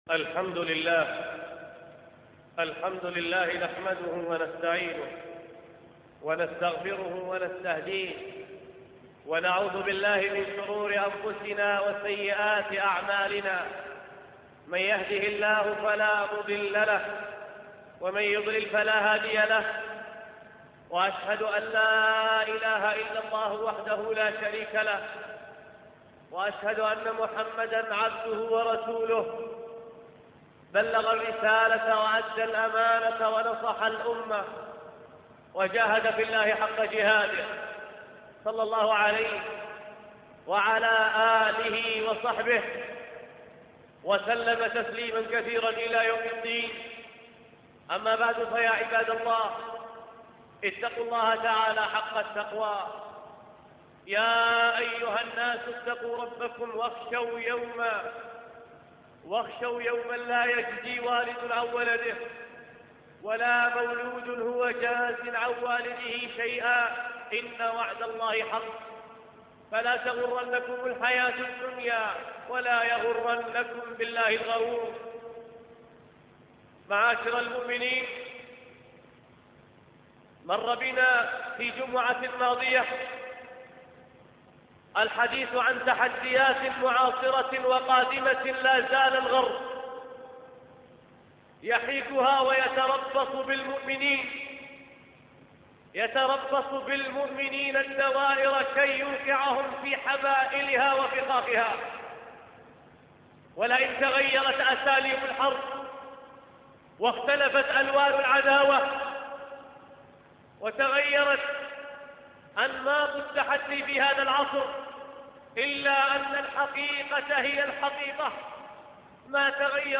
أرشيف صوتي لدروس وخطب ومحاضرات